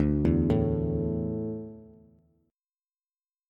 Dsus2 chord
D-Suspended 2nd-D-x,5,2,2-8-down-Bass.m4a